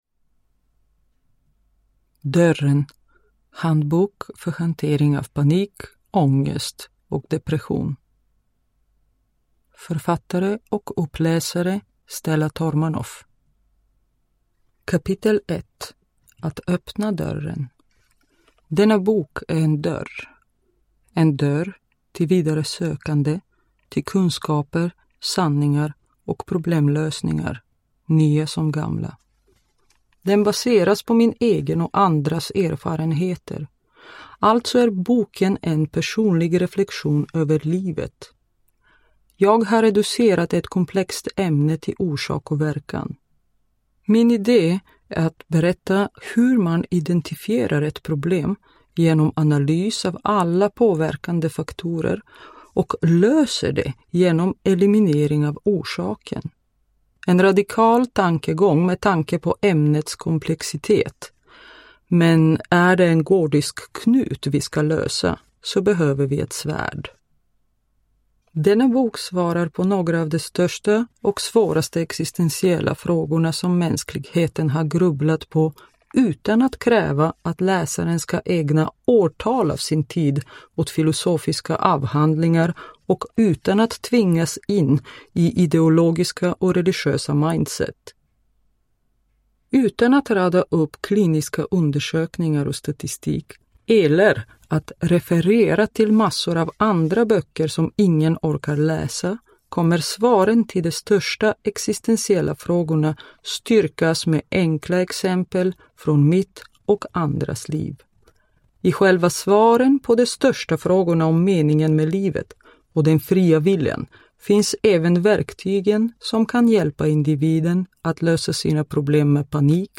Dörren – Ljudbok – Laddas ner